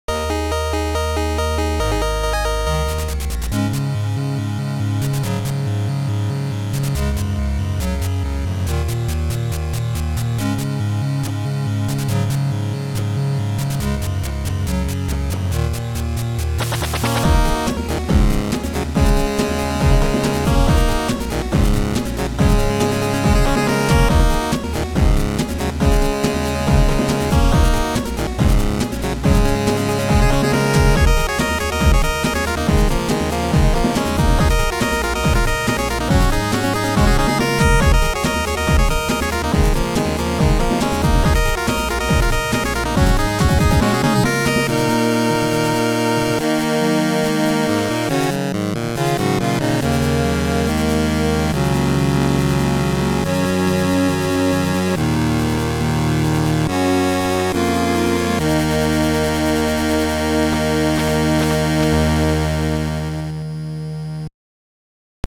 Good chiptune, I think.